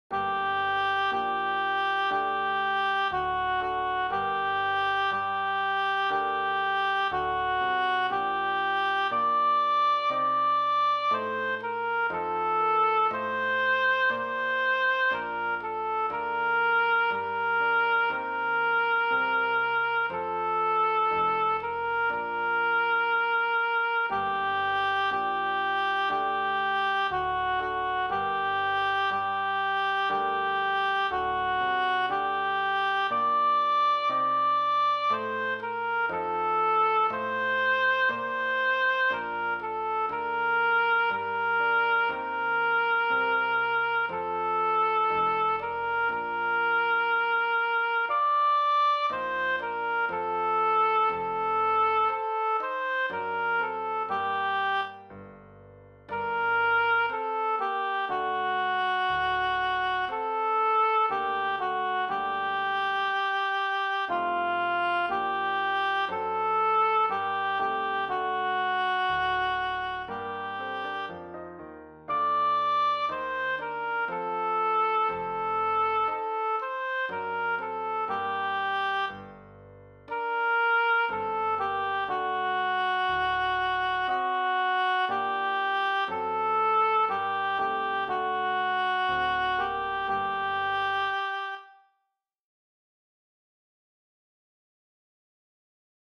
A.A. 24/25 Canto Corale
O-Dianne-Gluck-sol-minore-ALTO.mp3